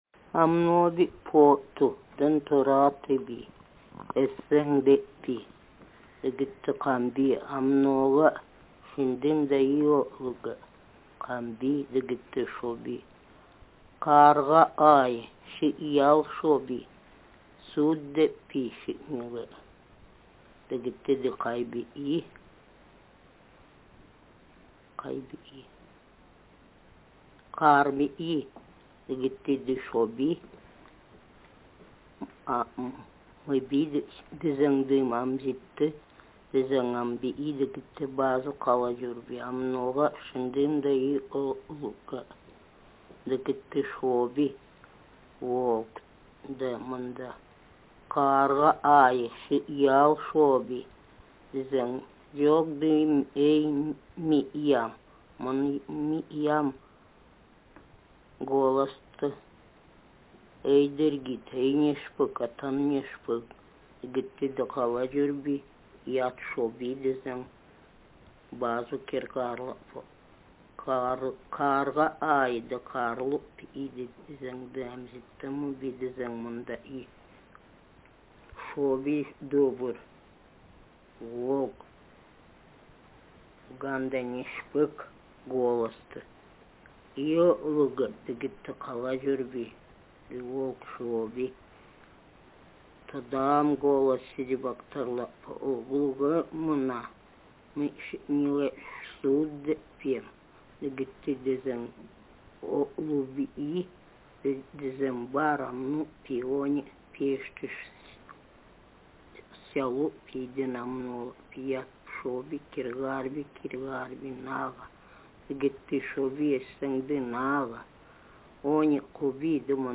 Speaker sexf
Text genretraditional narrative